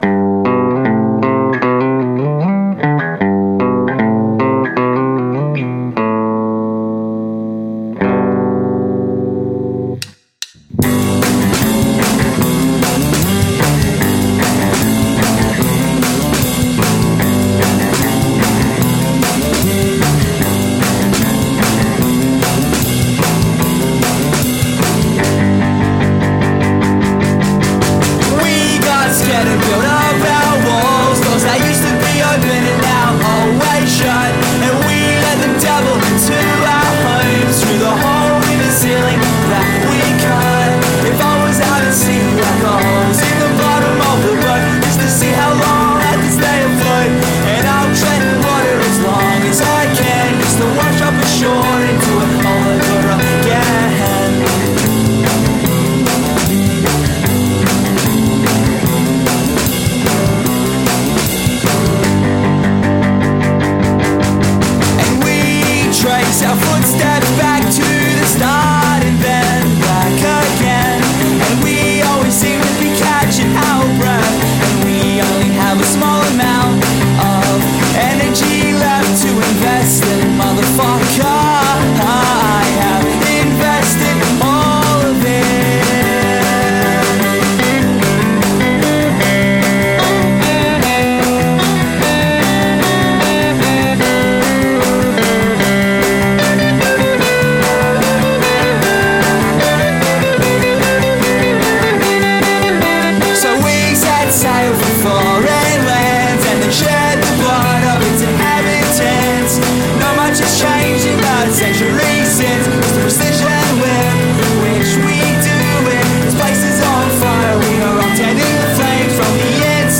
emo folk fusion
vocals, guitars, bass, piano as well as drums